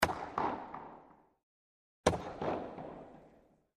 Puck Hits Board
Hockey: Puck Shot To Board ( 2x ); Solid Impact, Puck Hits Board; Clap / Echo / Puck Hits Ice, Close Perspective.